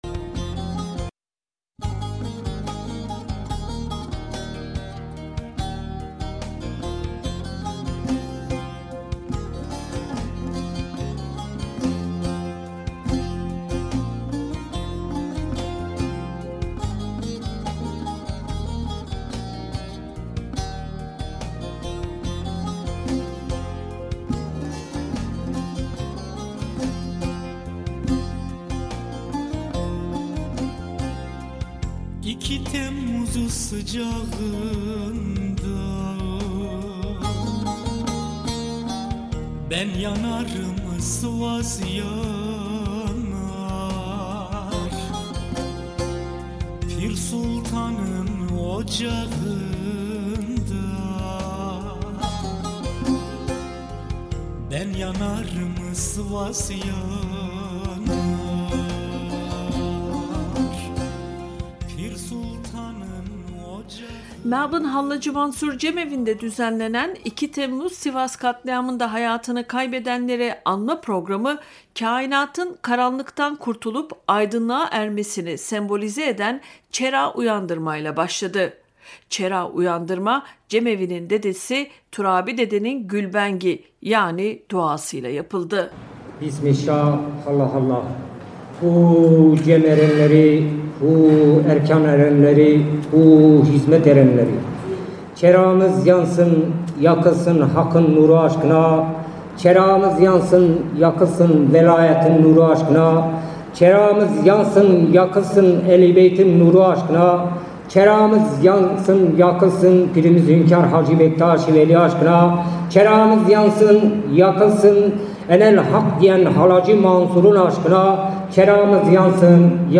Akşam ise Melbourne Hallacı Mansur Cemevinde yapılan anma programına çok sayıda toplum bireyi katıldı.
Anma programın katılan toplum bireylerimizle yaptığımız söyleşilerde özellikle gençlerin söyledikleri ilgi çekiciydi.
melbourne_hallaci_mansur_cemevindeki_2_temmuz_sivas_anmasi.mp3